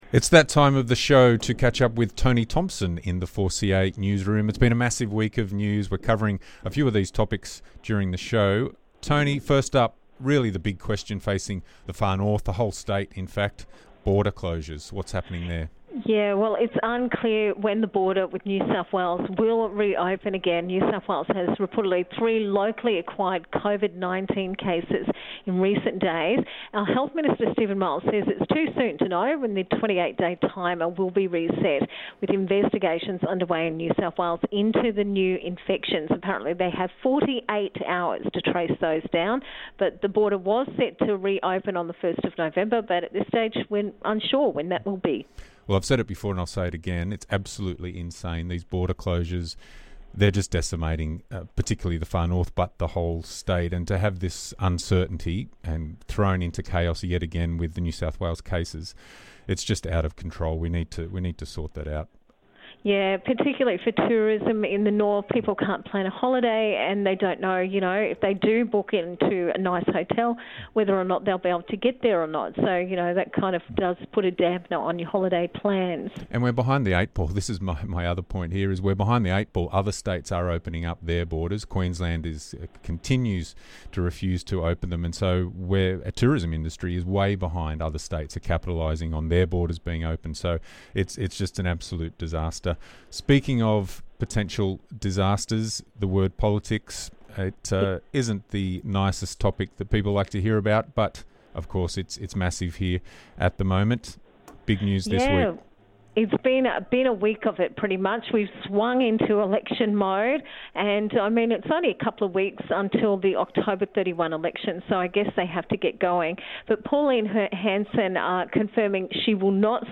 news wrap